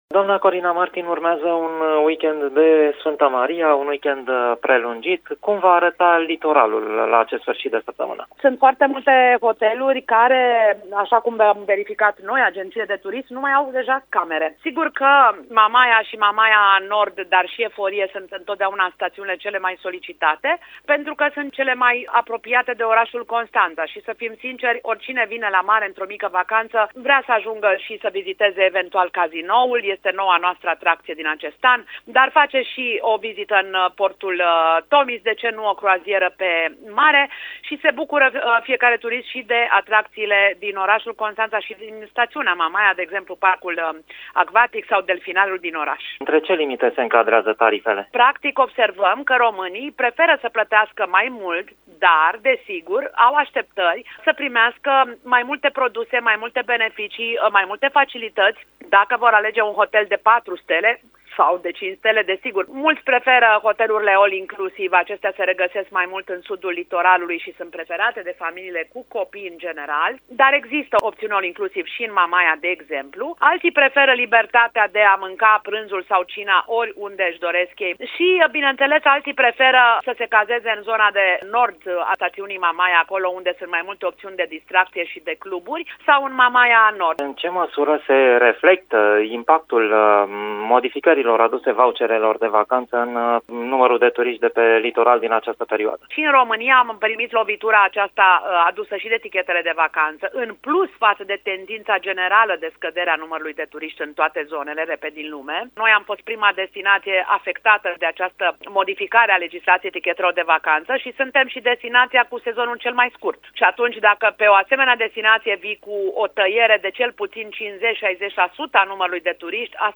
interviul următor